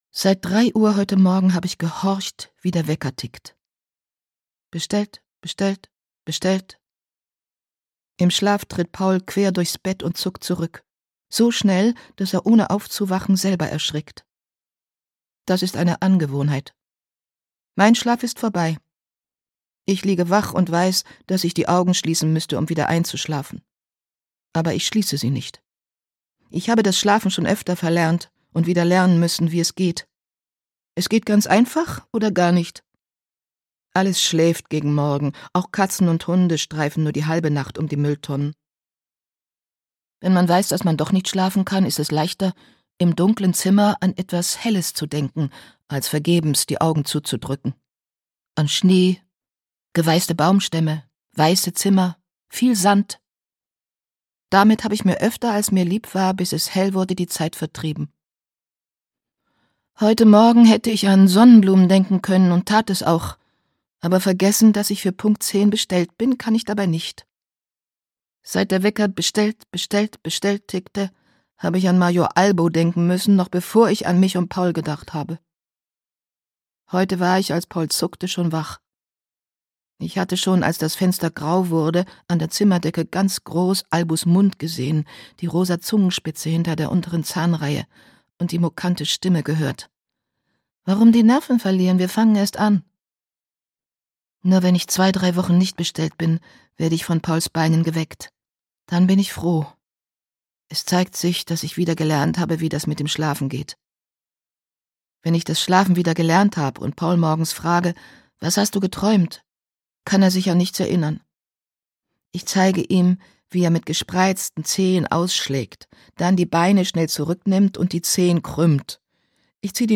Heute wär ich mir lieber nicht begegnet - Herta Müller - Hörbuch